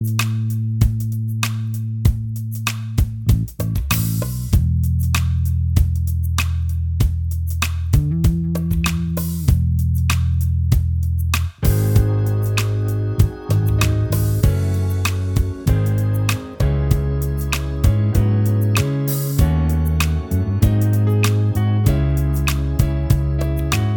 Minus All Guitars Soft Rock 3:58 Buy £1.50